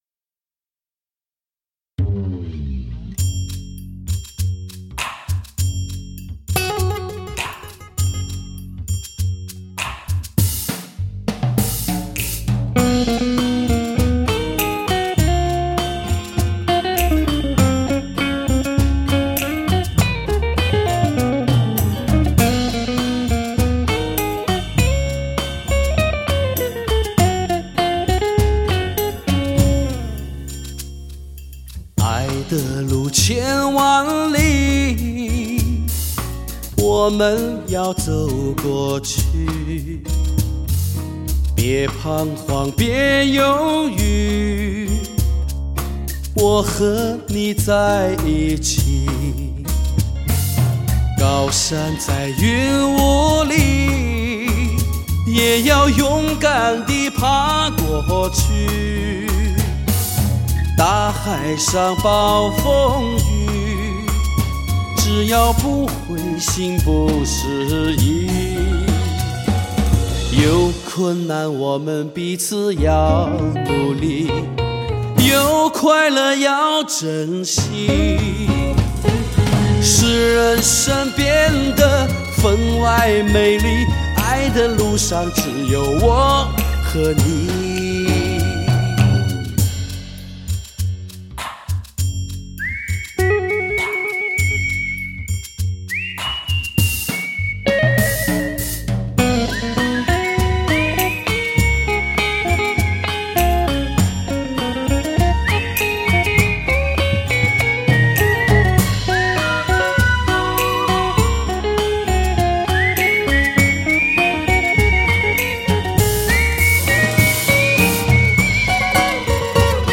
以CO发烧音乐手法制作，充满着时尚气味的HI-FI专辑。
这是一张通过最新音乐元素人声口技伴奏，勾起我们对过去的美好的回忆！